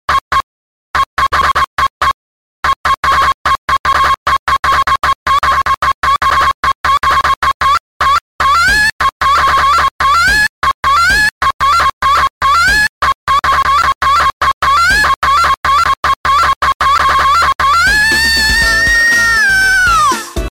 EMU OTORI SCREAMING (PAPAP DOL!!!)